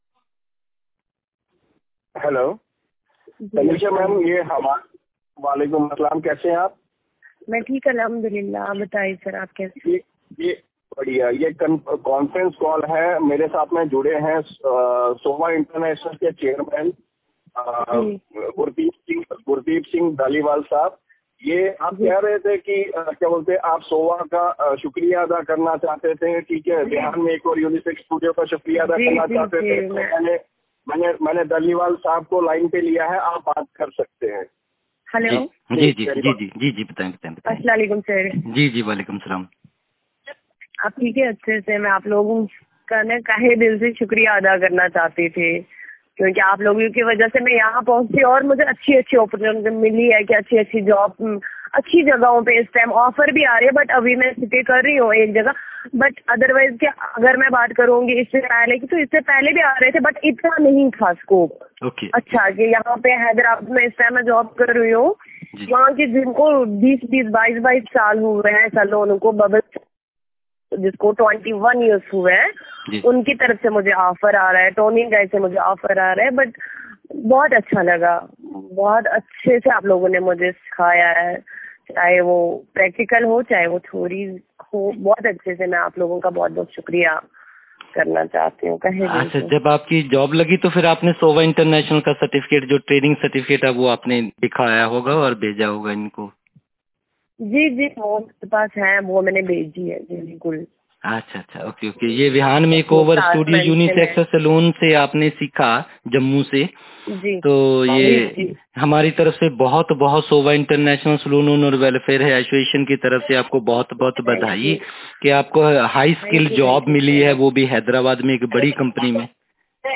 🎧 Audio Success Story